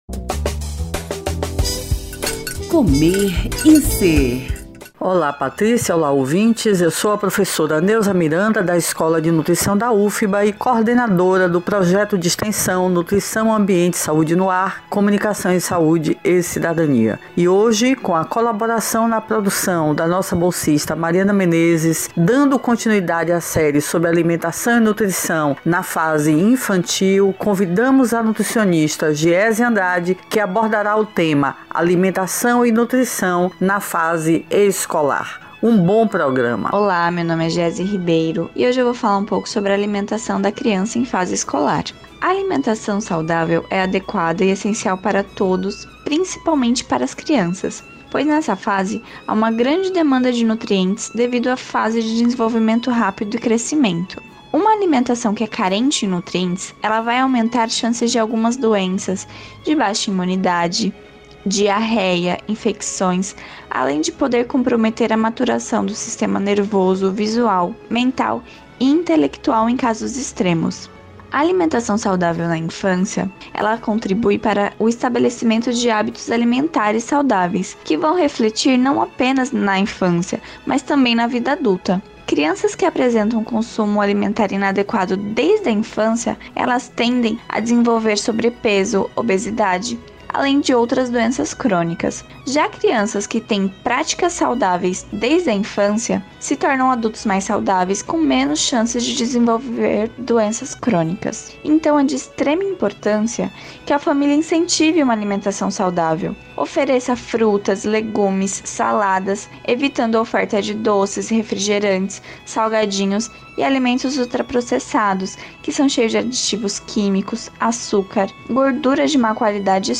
O assunto foi tema do quadro “Comer e Ser”, veiculado às Segundas-feiras pelo programa Saúde no ar, com transmissão pelas Rádios Excelsior AM 840  e  Web Saúde no ar.